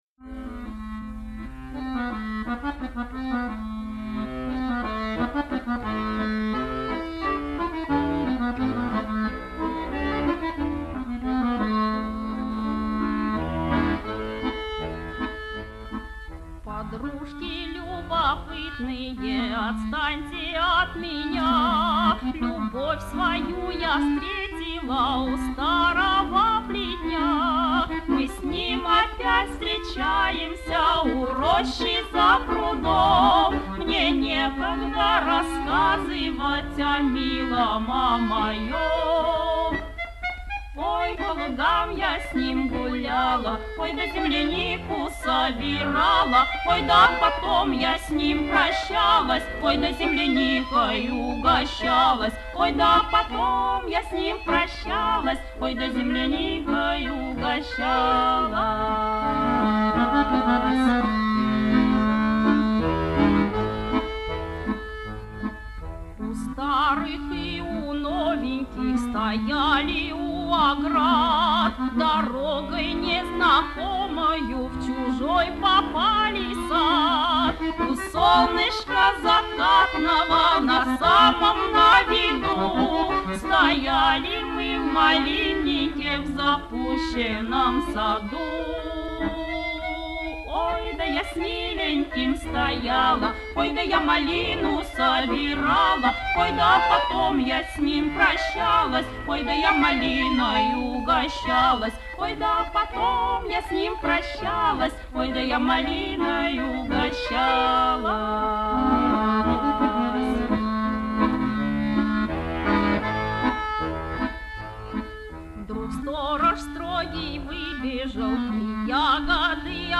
Не формат, но больно уж красивы баянные переливы.
Повторяем в улучшенном качестве, на время.
баян